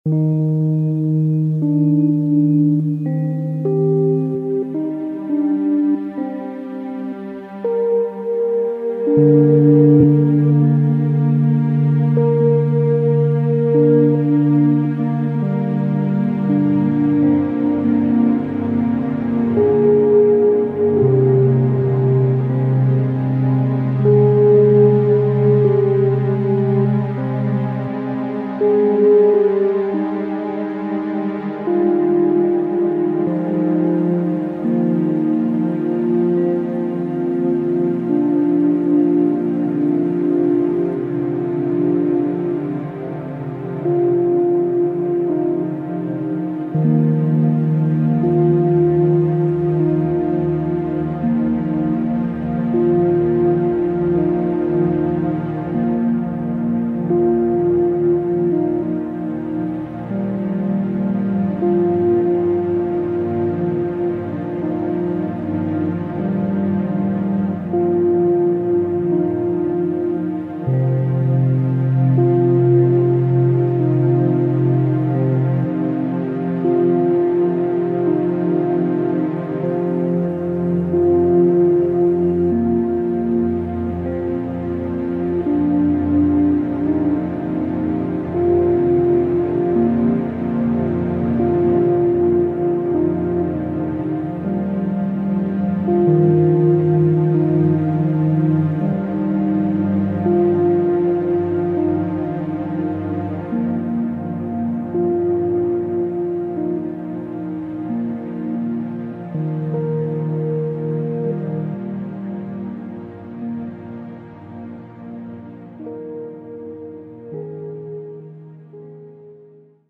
Yoga Music